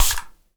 spray_bottle_05.wav